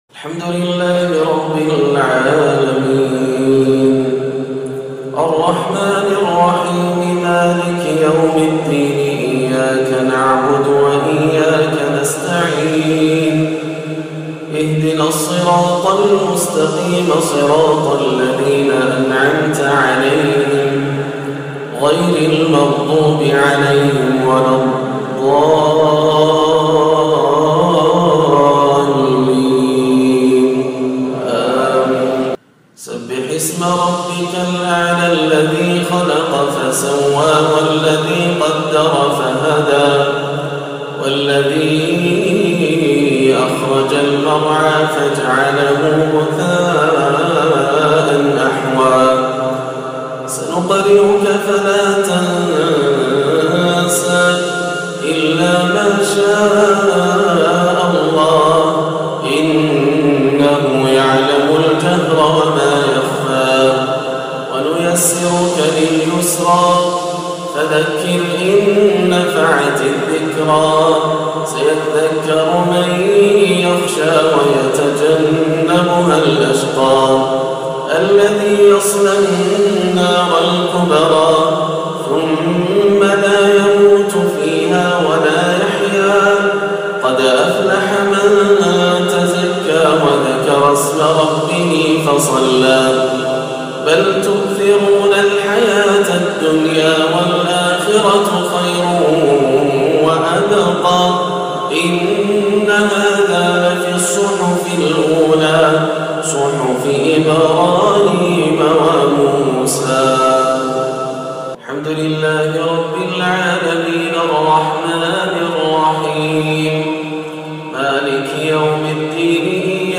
صلاة الاستسقاء 4-4-1437هـ سورتي الأعلى و الغاشية > عام 1437 > الفروض - تلاوات ياسر الدوسري